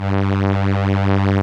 Index of /90_sSampleCDs/Keyboards of The 60's and 70's - CD1/STR_Elka Strings/STR_Elka Cellos
STR_ElkaVcG_3.wav